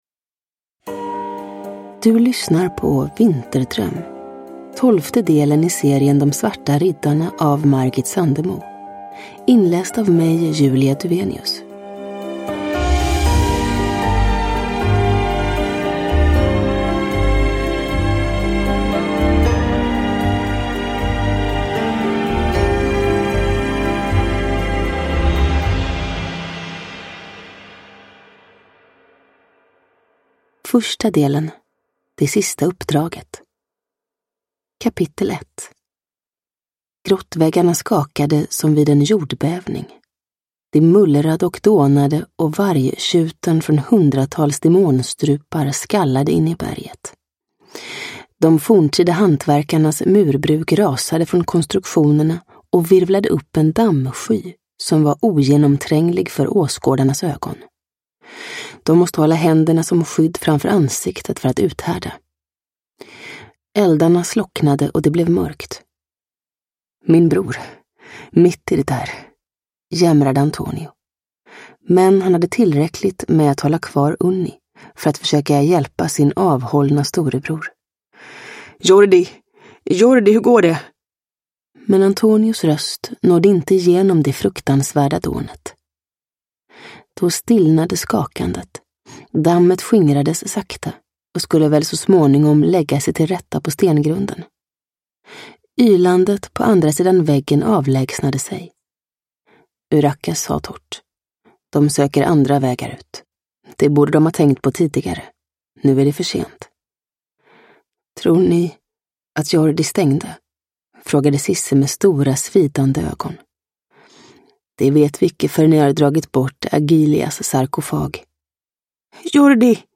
Vinterdröm – Ljudbok
Uppläsare: Julia Dufvenius